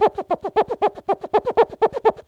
cartoon_squeaky_cleaning_loop_02.wav